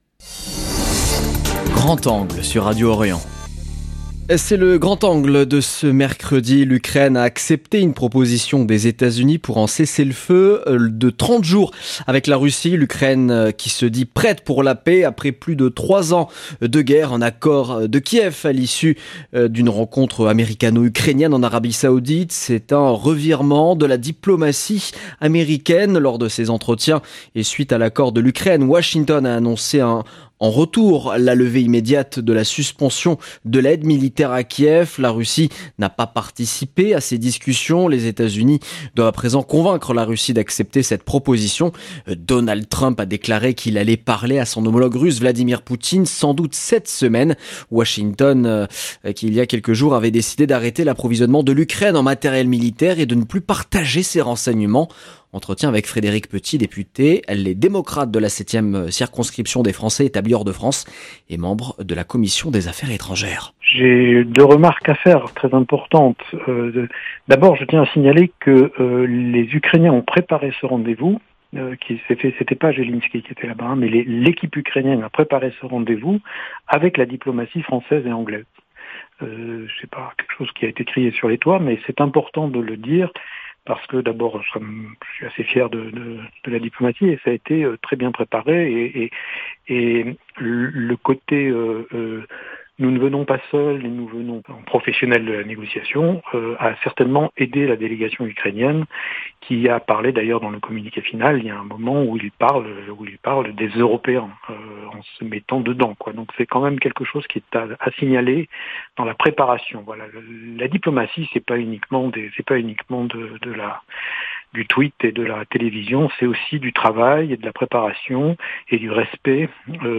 Entretien avec Frédéric Petit, député Les Démocrates de la 7e circonscription des Français établis hors de France, membre de la Commission des Affaires étrangèresC 0:00 9 min 56 sec